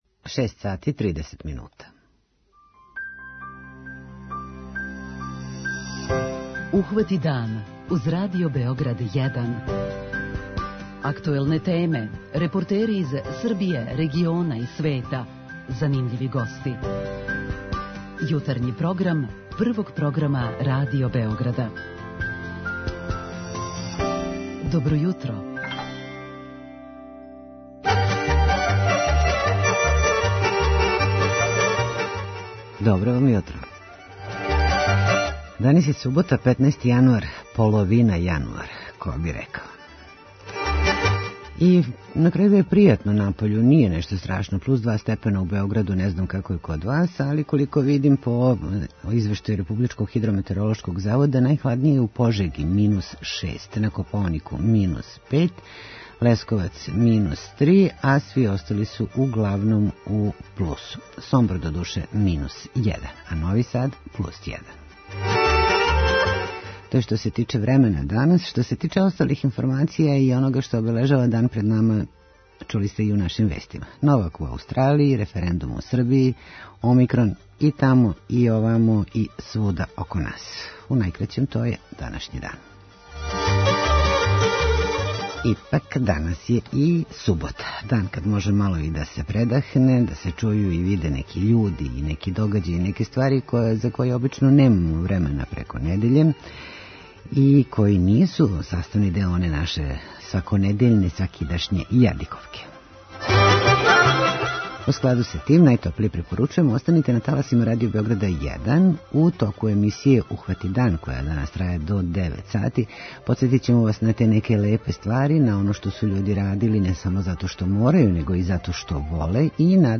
Станице се врло једноставно праве – а пружају помоћ, на начин достојан човека. преузми : 26.99 MB Ухвати дан Autor: Група аутора Јутарњи програм Радио Београда 1!